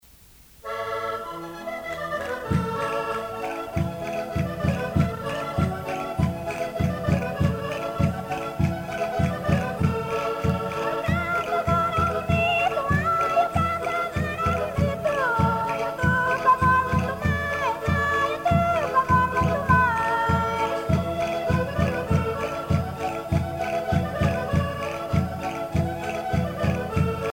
Chula
danse : vira (Portugal)
Grupo folclorico da Casa do Concelho de Arcos de Valdevez
Pièce musicale éditée